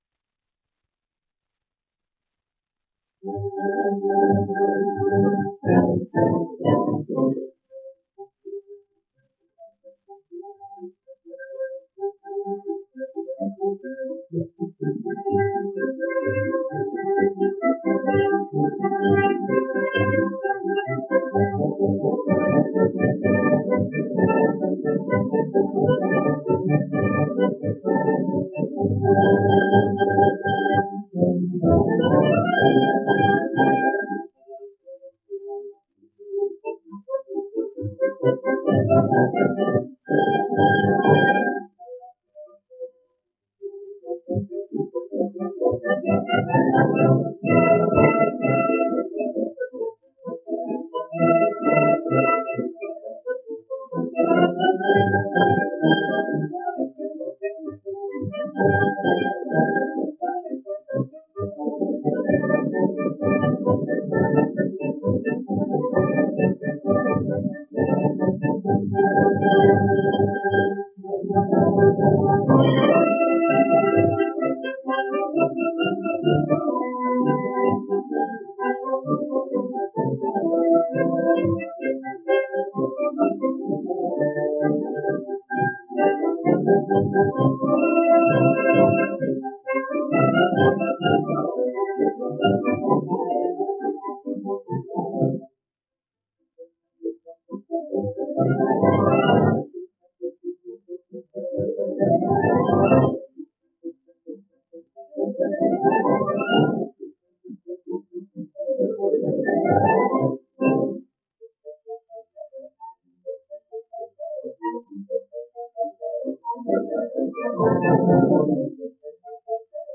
Los voluntarios (sonido remasterizado)